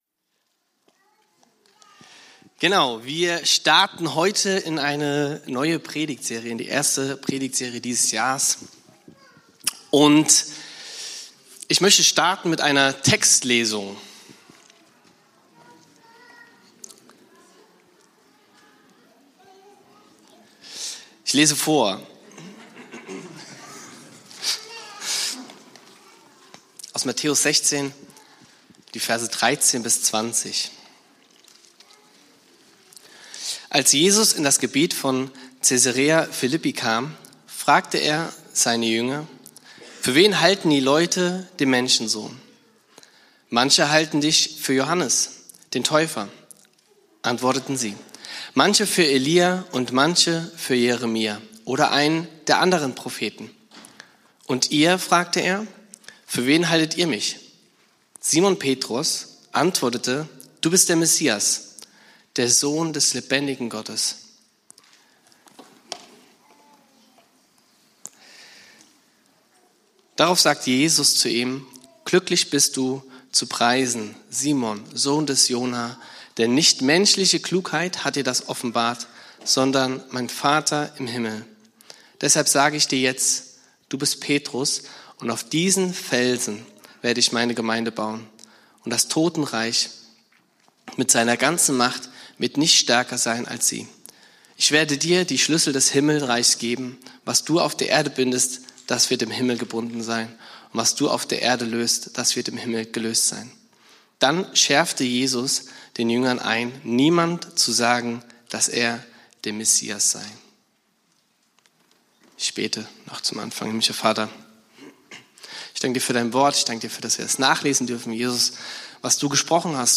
Der erste Teil der Predigtserie "Ekklesia".
Predigt vom 01.03.2026 in der Kirche für Siegen